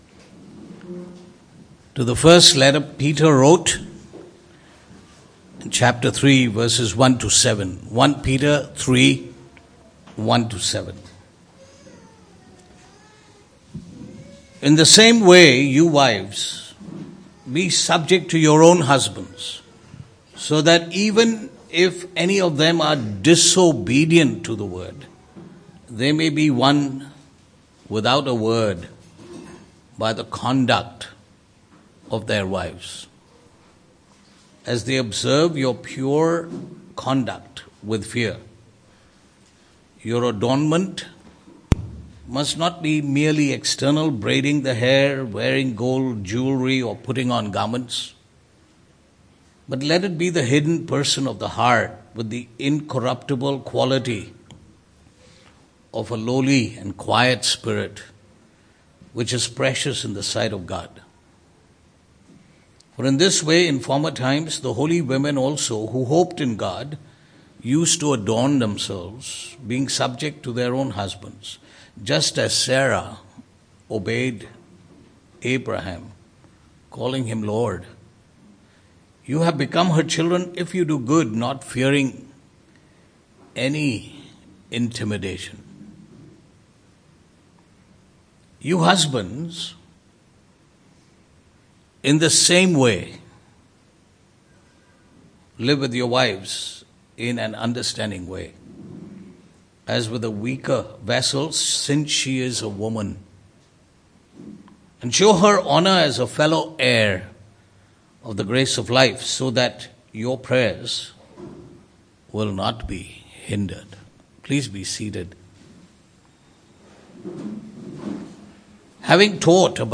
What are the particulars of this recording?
Passage: 1 Peter 3:1-7 Service Type: Sunday Morning